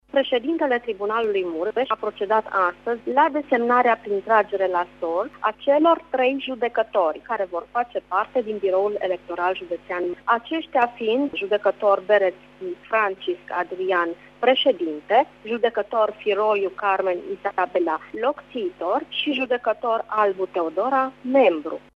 Președintele Tribunalului Mureș, judecător Veronica Szasz: